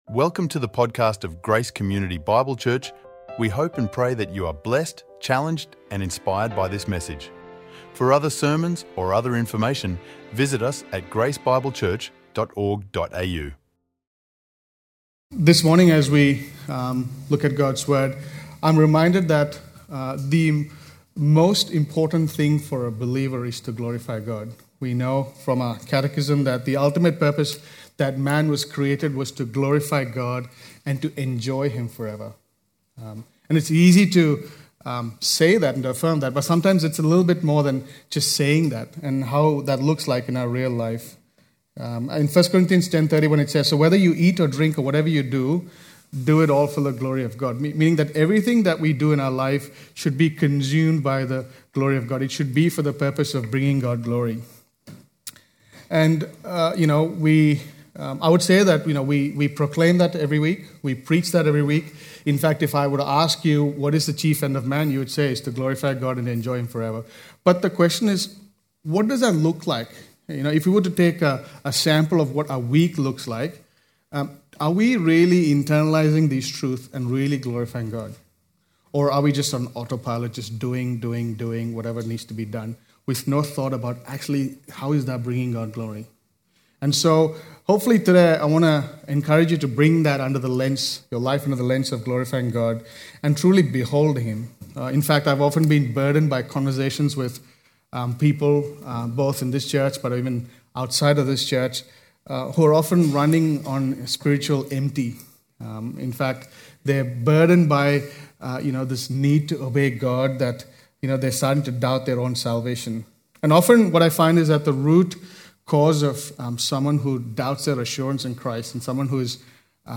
recorded live at Grace Community Bible Church